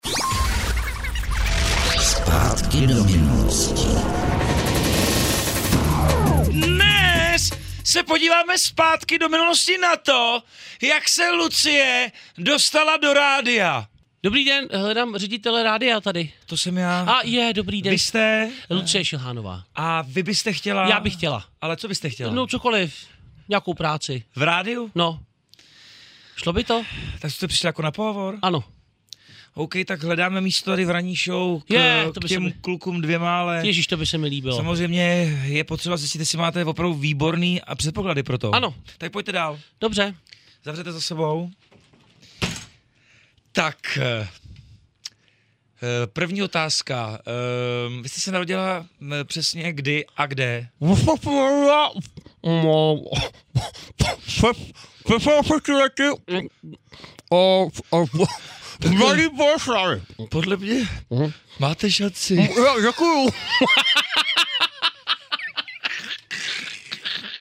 zpátky do minolostiDneska ráno mě brutálním způsobem rozstřelila ranní show na Evropě 2.